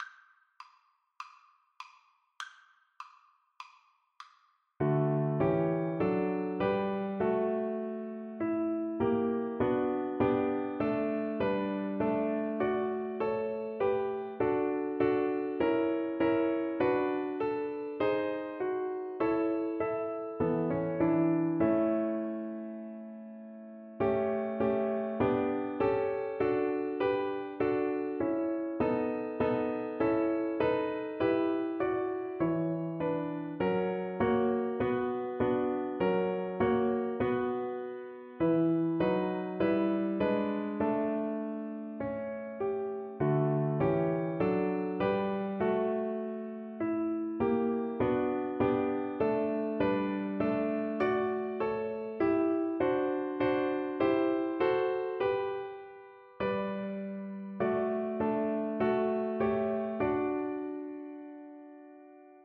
Viola
4/4 (View more 4/4 Music)
D major (Sounding Pitch) (View more D major Music for Viola )
Classical (View more Classical Viola Music)